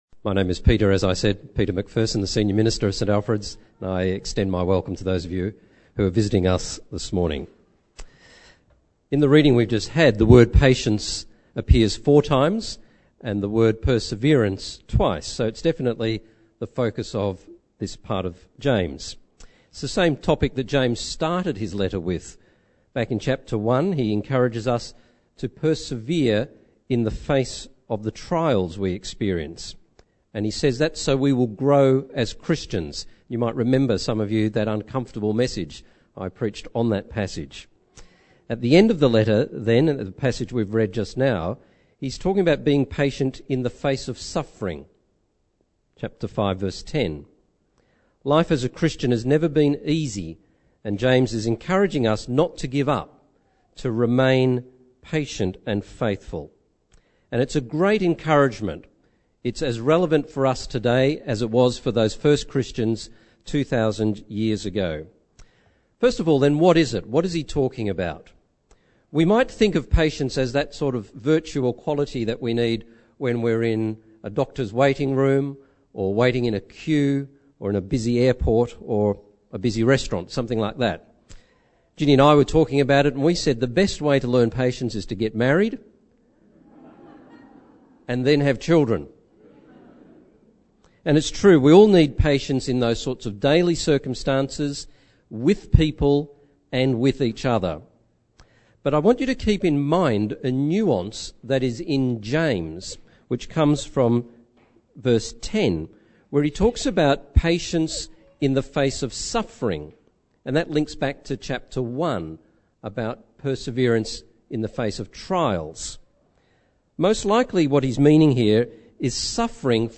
Sermons | St Alfred's Anglican Church
Bible Passage